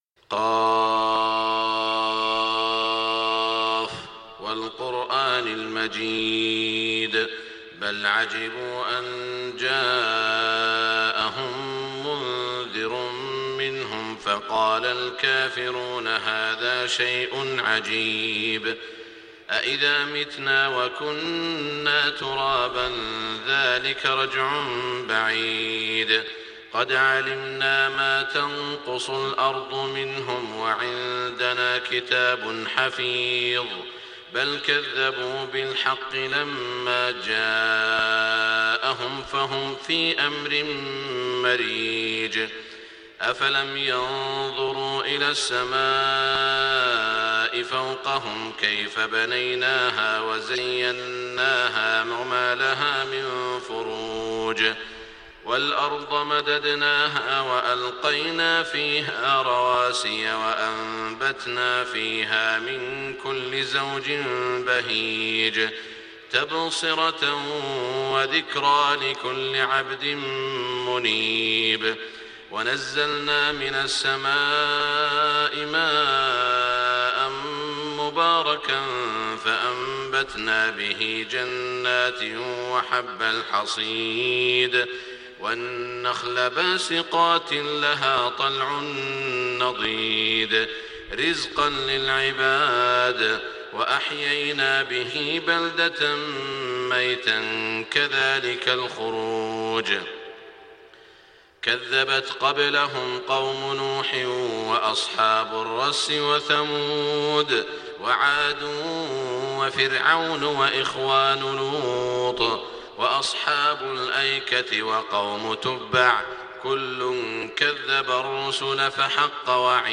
صلاة الفجر 1427هـ من سورة ق > 1427 🕋 > الفروض - تلاوات الحرمين